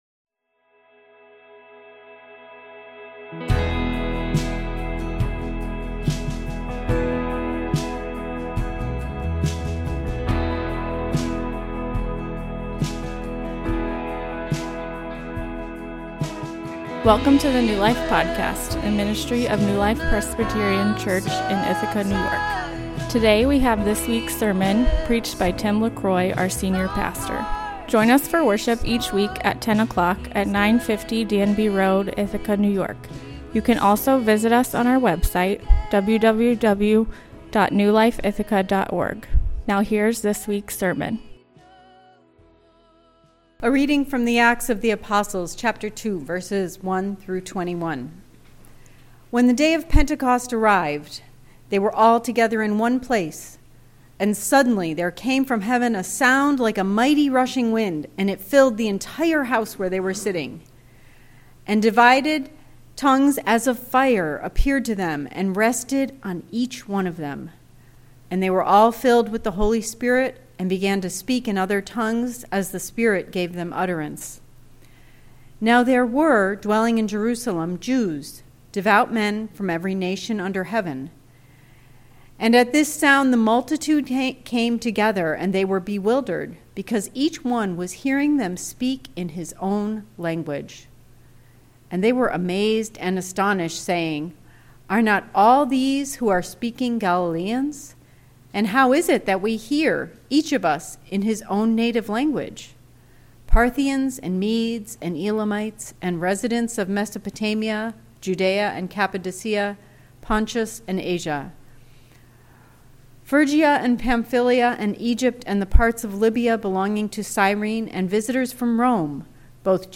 What if God himself moved into your house and became a part of your family? A Sermon for Pentecost Outline: I. God is with His people II.